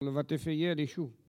Collectif-Patois (atlas linguistique n°52)